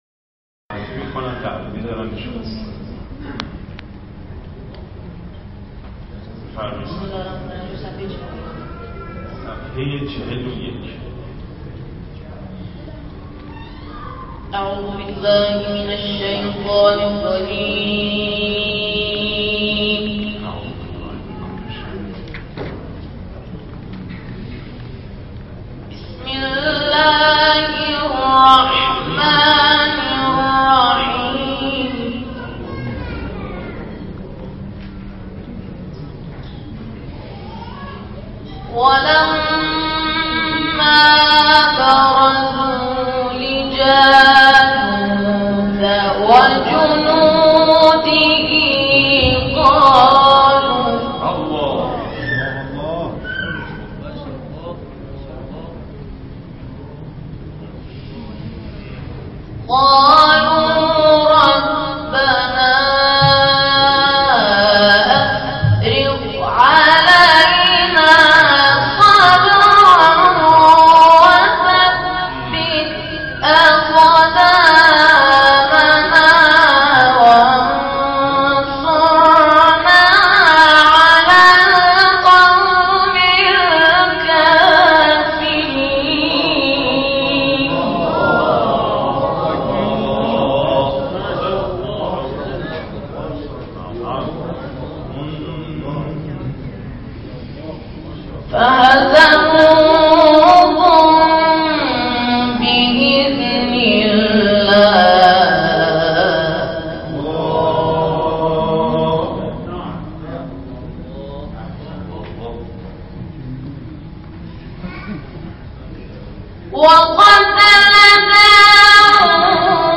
تلاوت
ولما برزوا و طارق (نهاوند